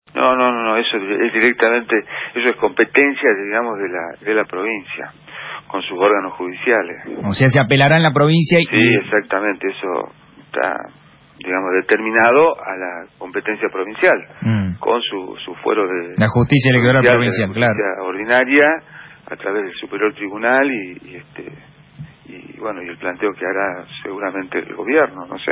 En declaraciones a Radio Fénix, el magistrado federal aclaró que la discusión judicial debe darse en la justicia provincial, al desligar responsabilidad a su juzgado.